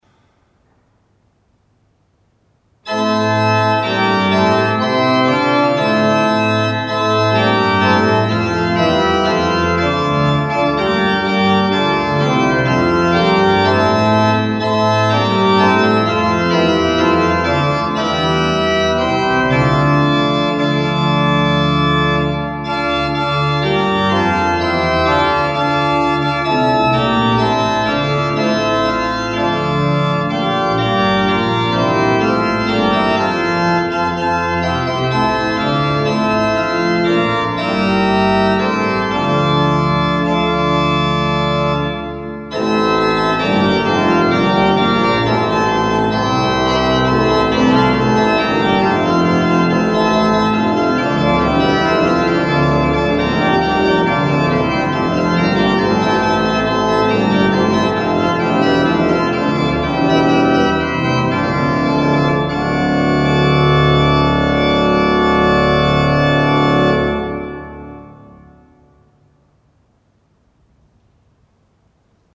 Wir wollen alle fröhlich sein Orgel